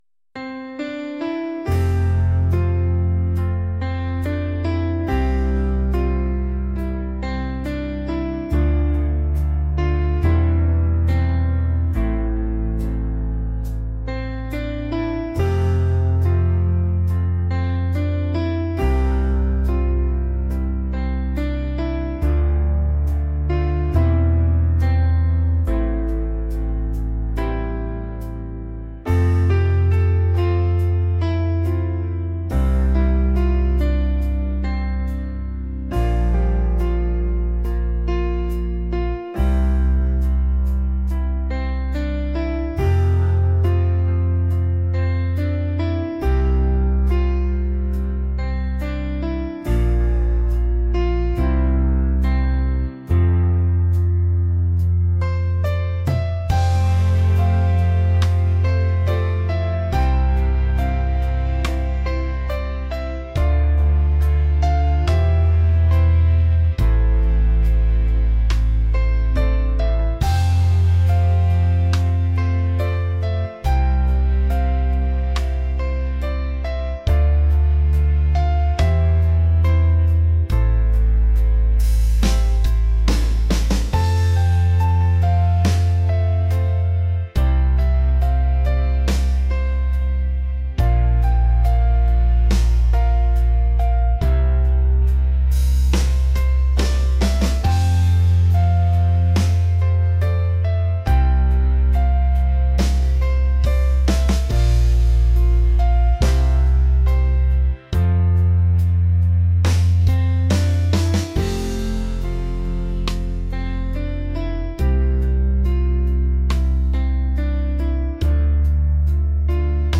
romantic | pop